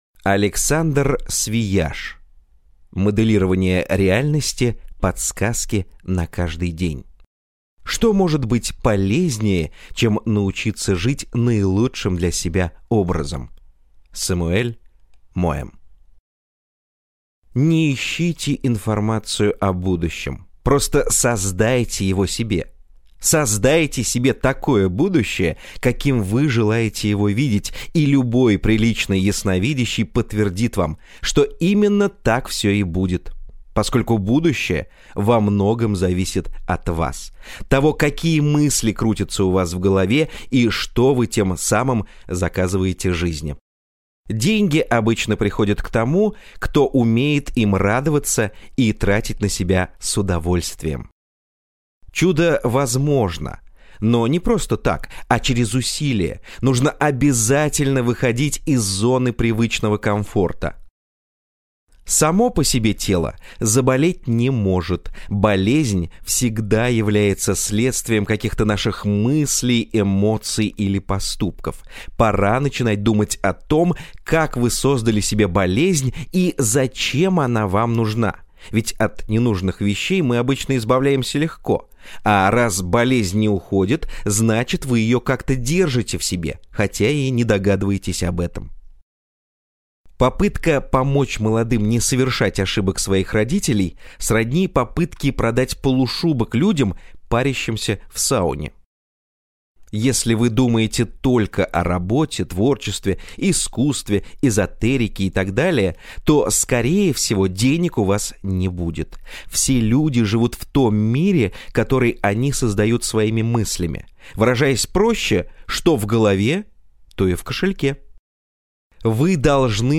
Аудиокнига Моделирование реальности. Подсказки на каждый день | Библиотека аудиокниг